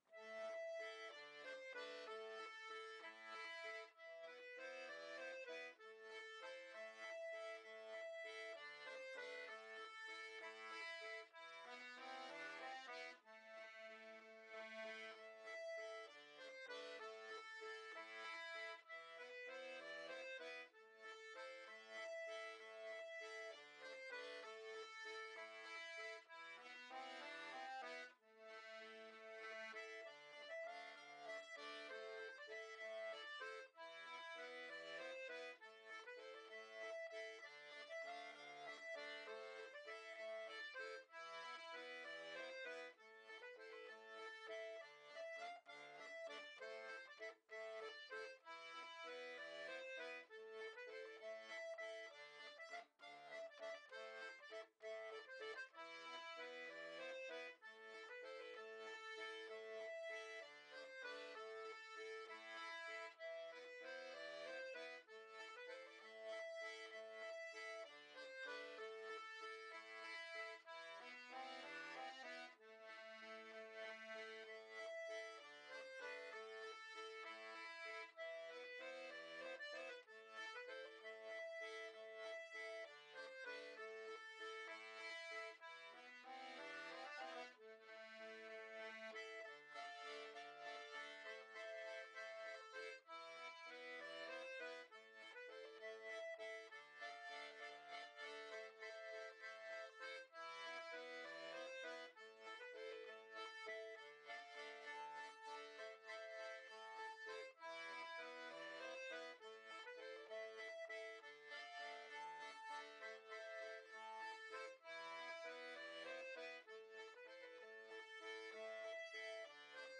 Musique : Varblomor à l'accordéon diatonique
Valse traditionnelle suédoise - 1,90 Mo - 2 mn 30 :